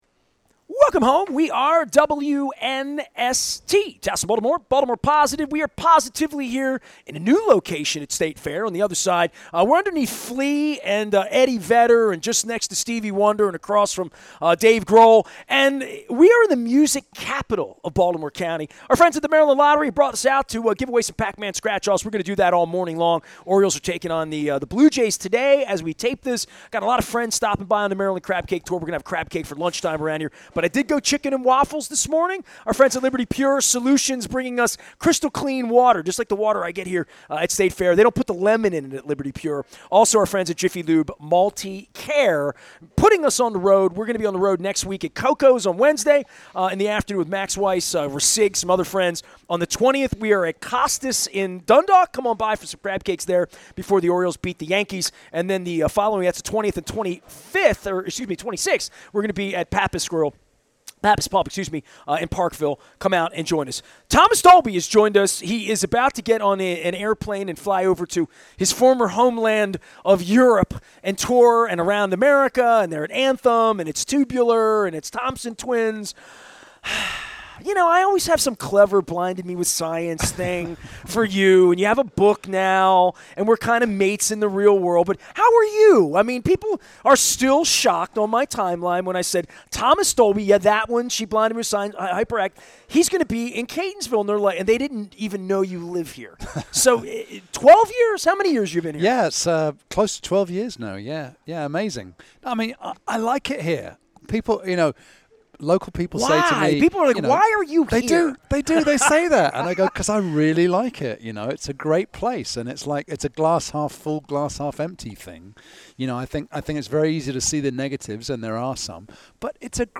at State Fare on the Maryland Crab Cake Tour